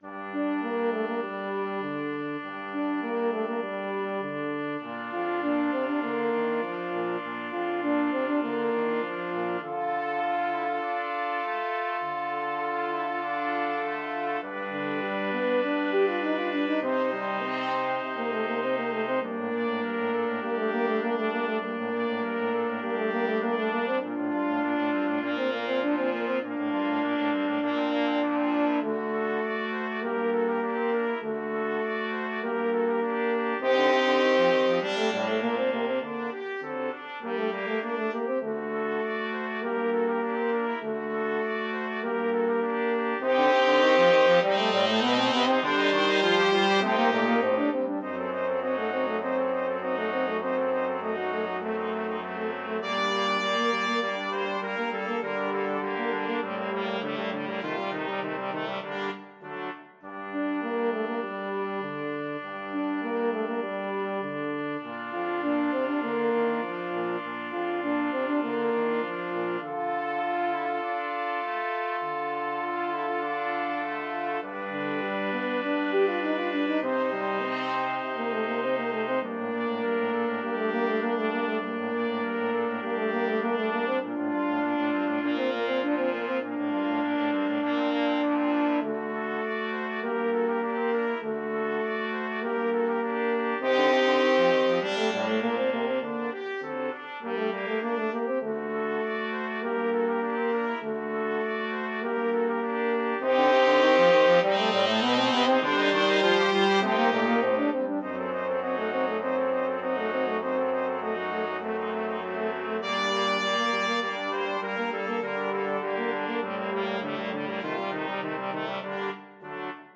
Free Sheet music for Flexible Brass Ensemble - 4 Players
Trumpet in BbTuba
French Horn in FTuba
TromboneTuba
4/4 (View more 4/4 Music)
G minor (Sounding Pitch) (View more G minor Music for Flexible Brass Ensemble - 4 Players )
Allegro Moderato (View more music marked Allegro)
Classical (View more Classical Flexible Brass Ensemble - 4 Players Music)